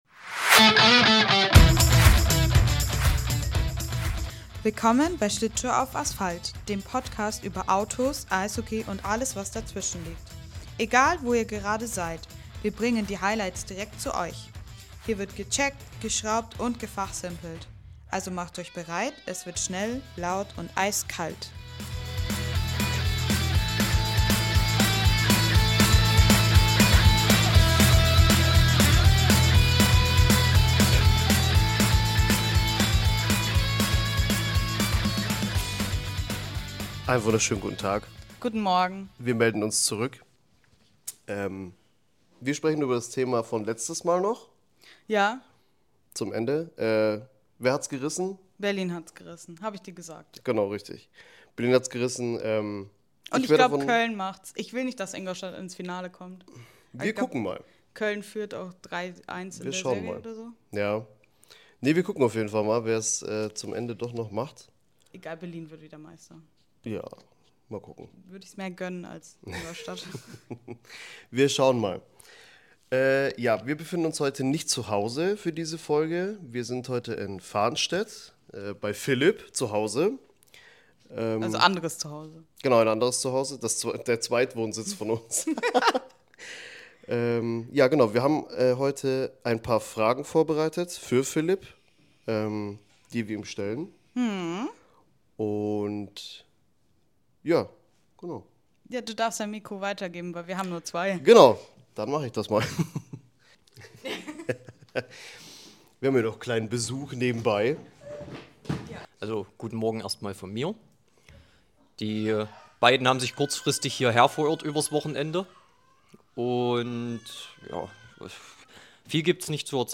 Heute haben wir einen ganz besonderen Gast bei uns – einen guten Freund, KfZler und Tuning-Fan. Er beantwortet uns ein paar Fragen und erzählt uns, wie er seinen Familienalltag als Papa und seine Leidenschaft fürs Auto-Umbauen unter einen Hut bekommt.
Ein lockeres Gespräch, das sich wie ein gemütlicher Nachmittag in der Garage anfühlt.